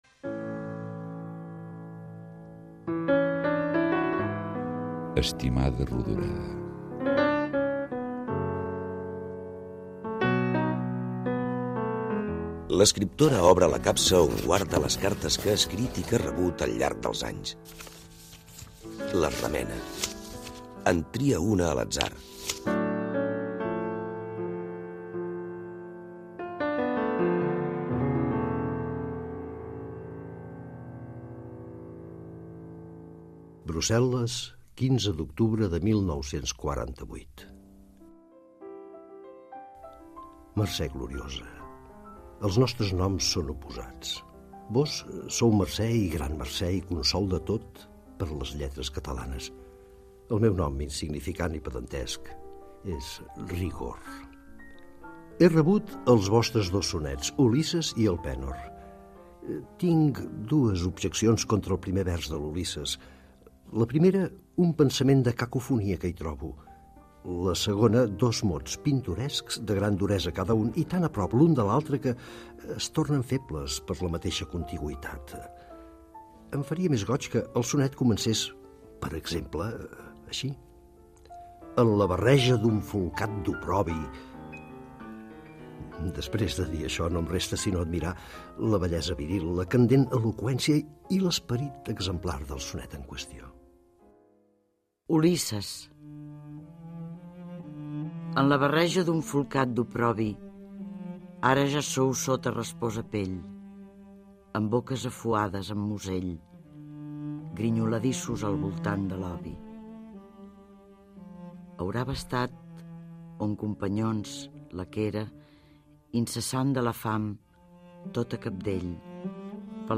Careta del programa. Cartes de Josep Carner adreçades a Mercè Rodoreda i la seva resposta
Ficció